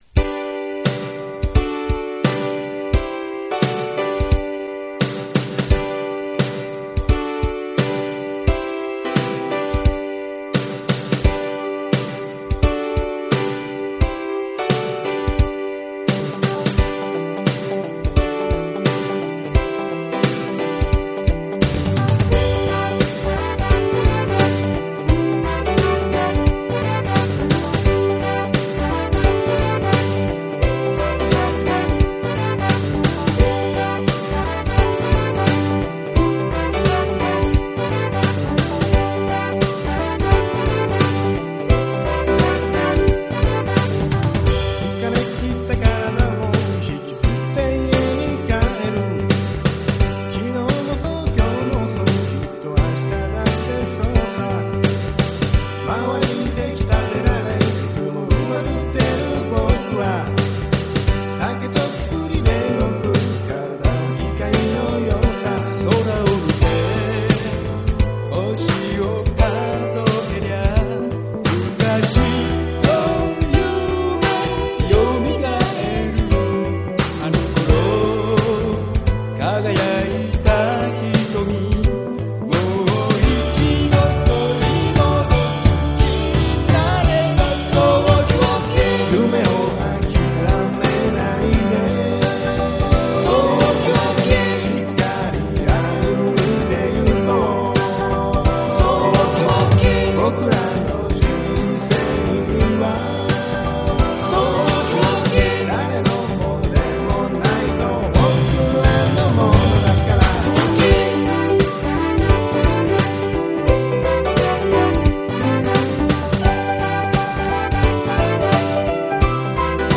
サウンド クオリティーかなり良い。